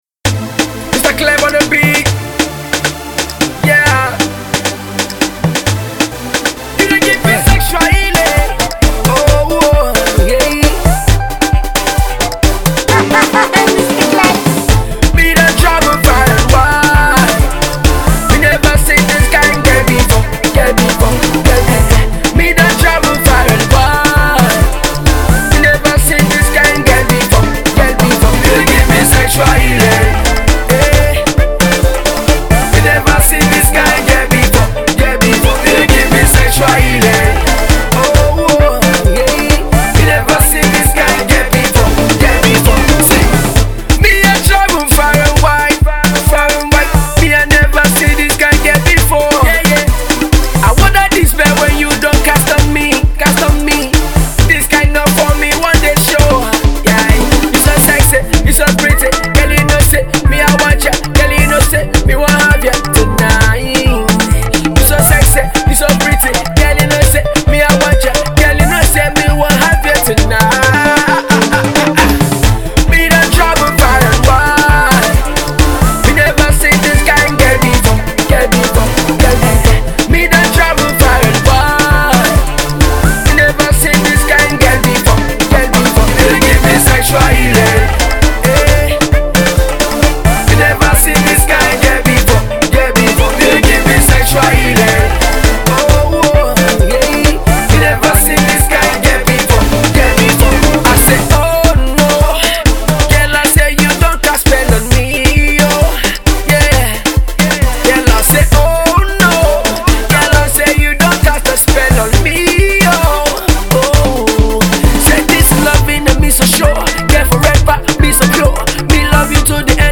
A danceable tune